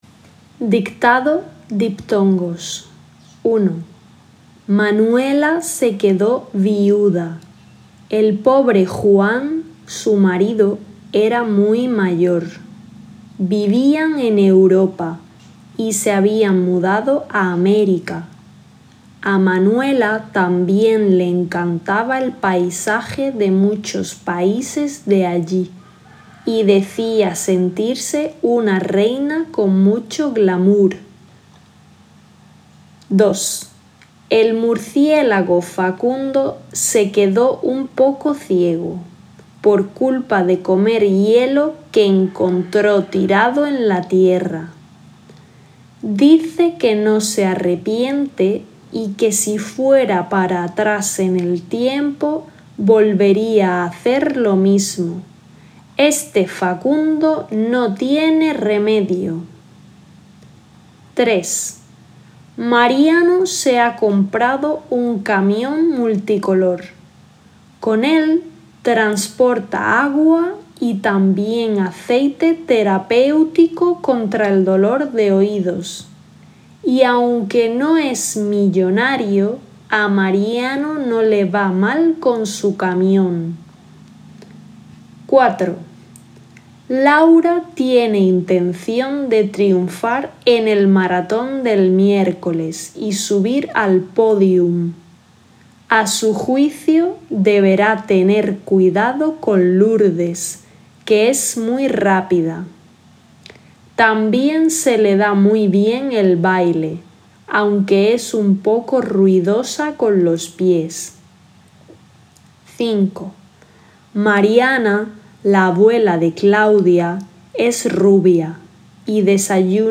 MGulaRZj0fc_Dictado-diptongos.m4a